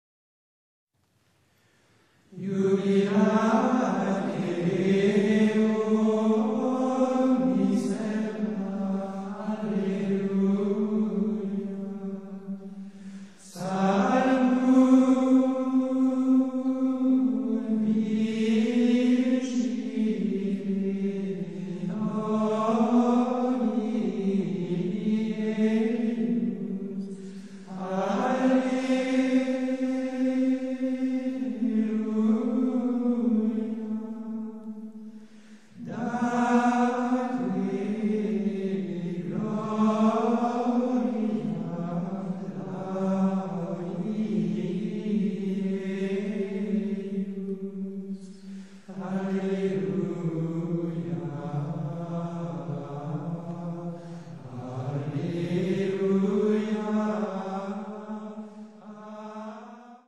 Стиль пения, принятый в Солеме, известен необычайной ритмической гибкостью, порождающей особый созерцательный эффект.
The Choir Of The Benedictine Monks Of The Abbey St.Pierre Of Solesmes – Gregorian chant: the collection